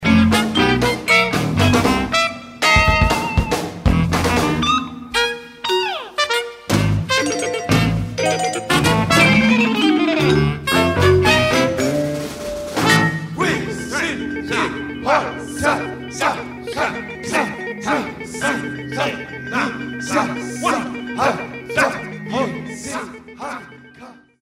A must for fans of avant-rock madness.
electric bass
drums
electric guitar
soprano sax
trumpet
bass clarinet
vibraphone